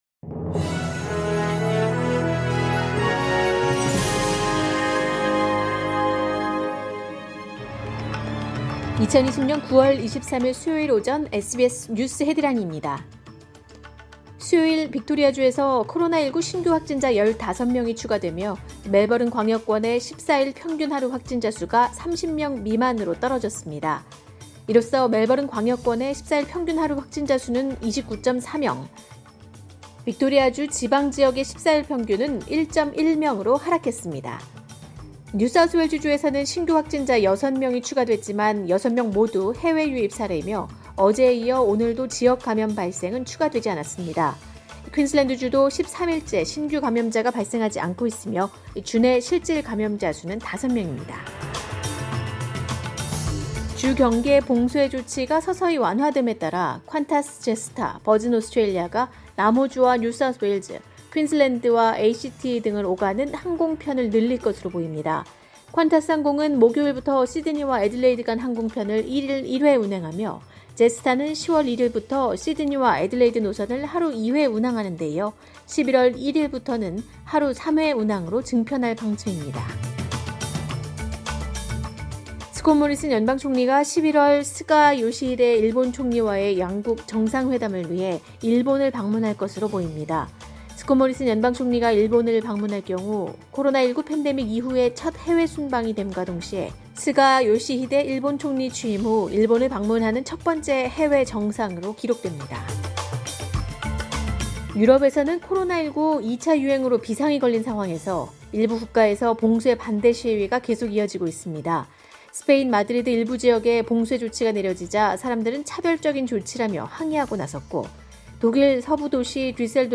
[SBS News Headlines] 2020년 9월 23일 오전 주요 뉴스
2020년 9월 23일 수요일 오전의 SBS 뉴스 헤드라인입니다.